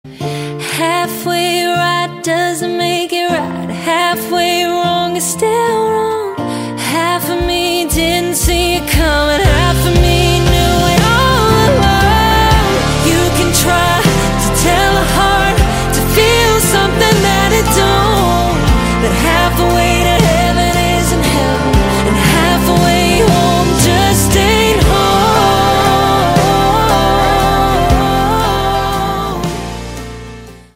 Classique ,Pop